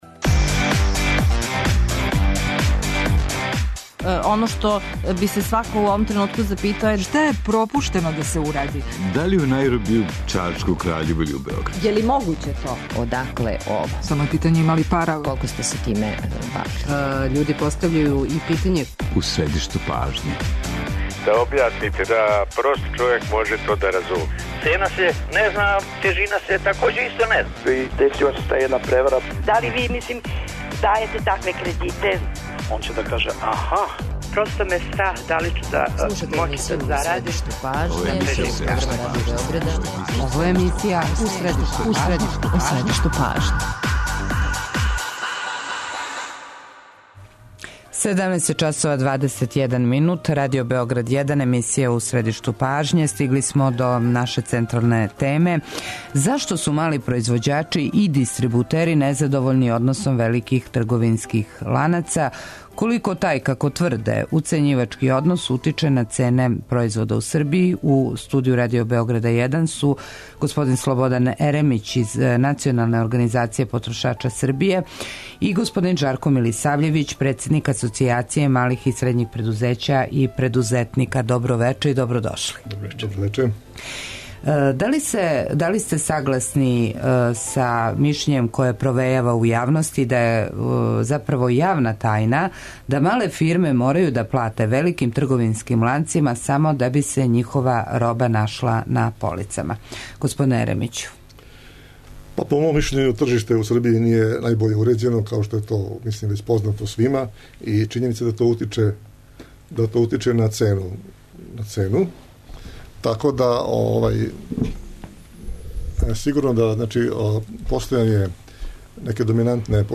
У емисији ћете чути и мишљење оних који су спремни да говоре о изнуђеним трошковима, тзв. "рекету" за полице